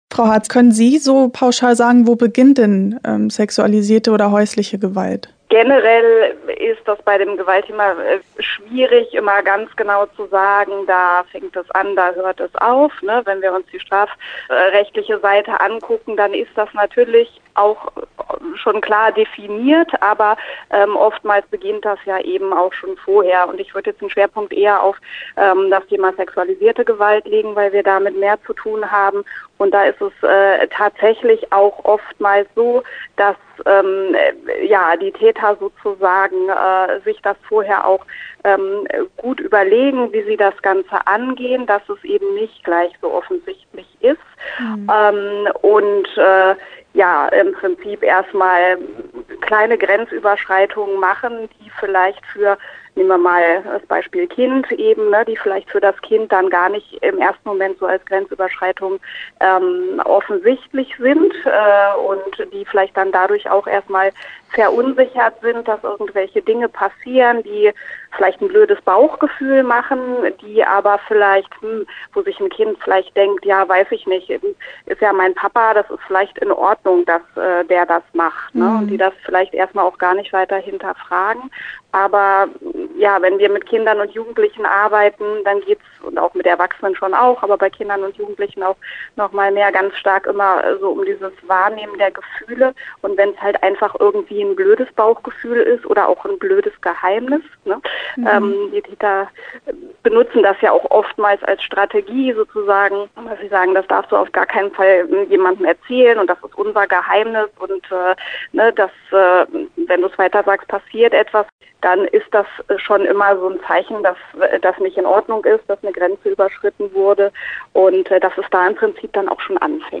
Interview-Hilfe-fuer-Gewaltopfer_mw.mp3